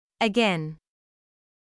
/k/・/g/は、音声学的には「破裂音」に分類されます。
破裂させるとき、/k/は息だけで、/g/は喉を震わせます。